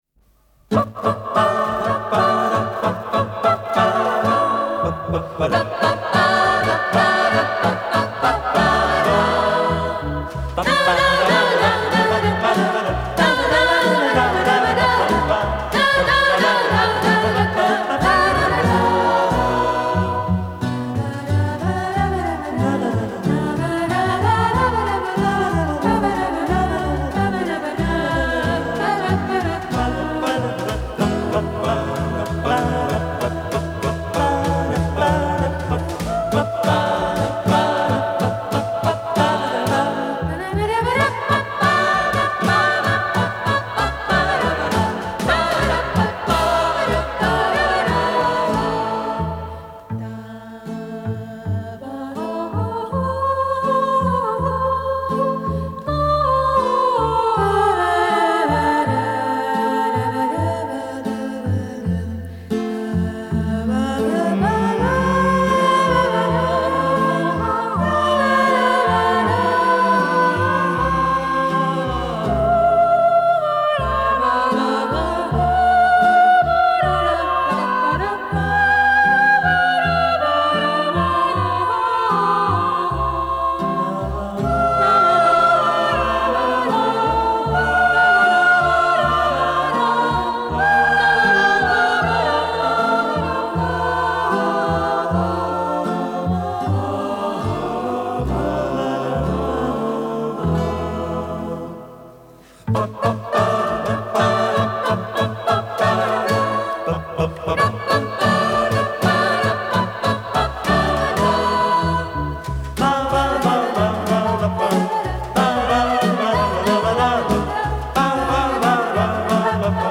Исполнитель: 1. Вокально-инструментальный ансамбль 2. Инструментальный ансамбль с электроинструментами и вокалом 3. Вокально-инструментальный ансамбль 4. Эстрадно-симфонический оркестр 5. Дуэт арф 6. Вокально-инструментальный ансамбль